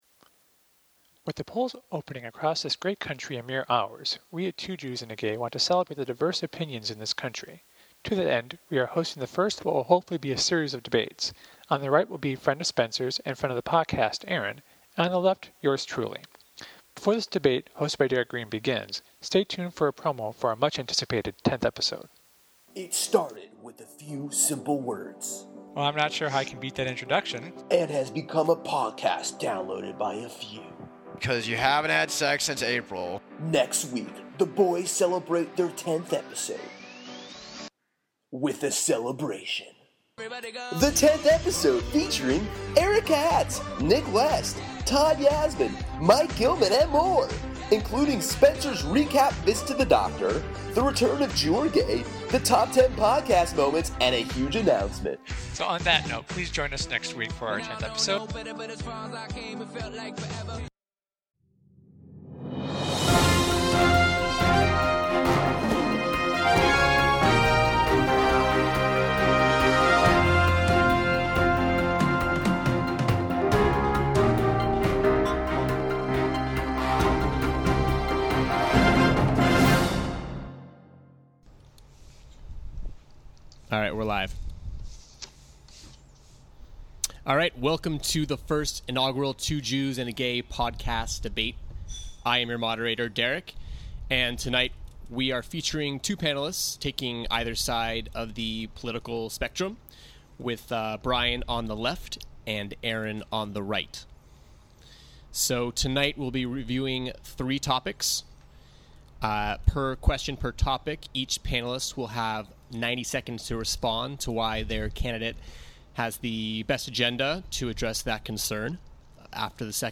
Listen to the debate and then check back in to the website and vote for who won!